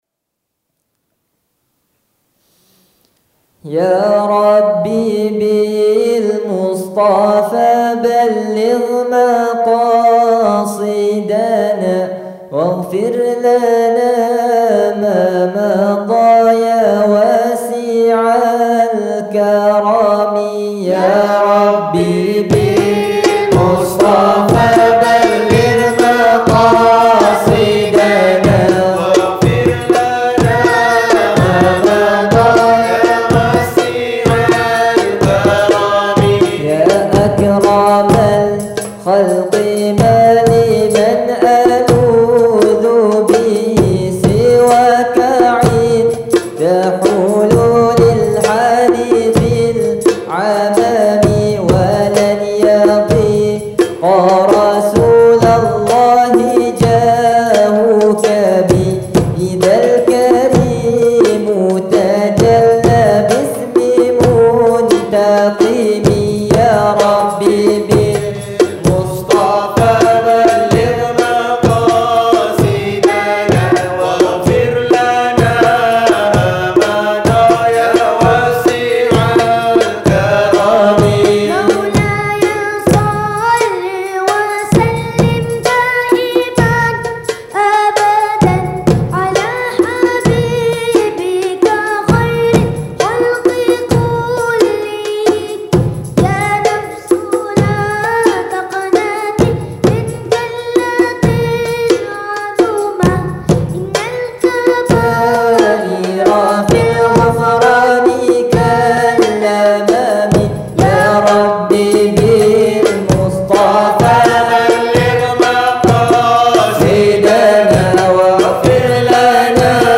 Jamming session with the Relite team
Relite Qasidah Club audio recordings by Relite Jamming session with the Relite team